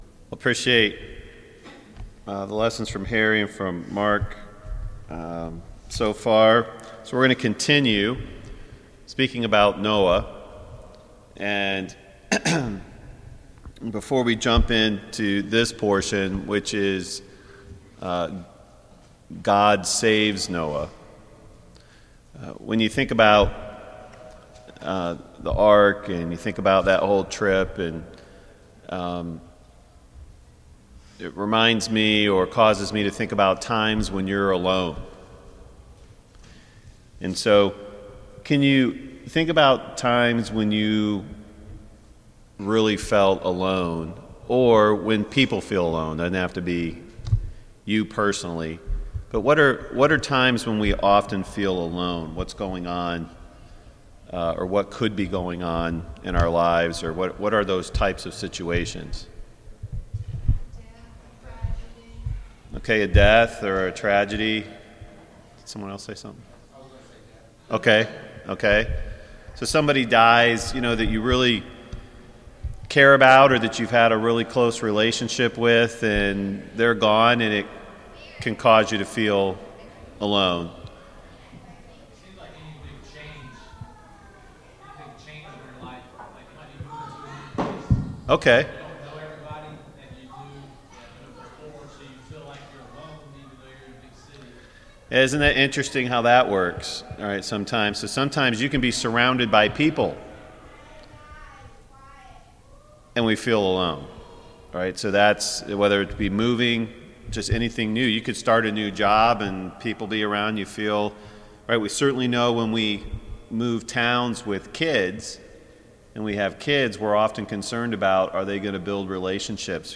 Service Type: VBS Adult Class